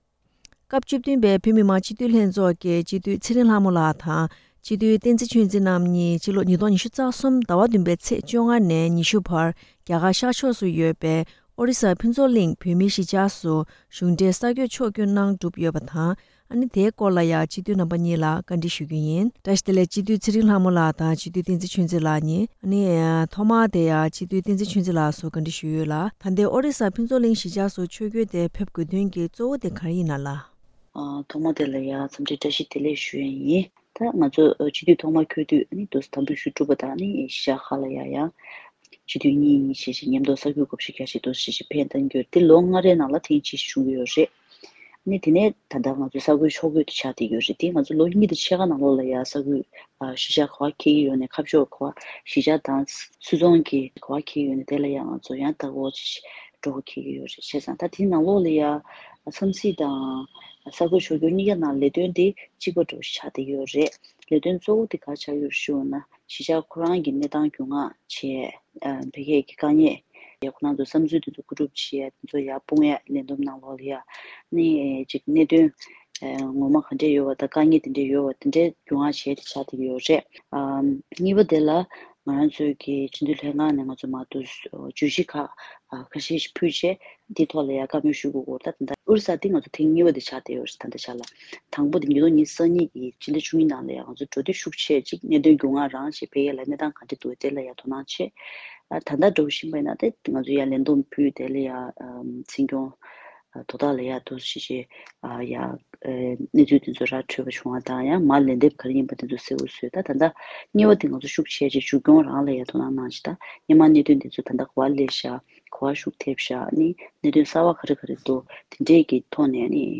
སྤྱི་འཐུས་རྣམ་པ་གཉིས་ལ་བཀའ་འདྲི་བཞུ་པ་ཞིག་གསན་རོགས་གནང་།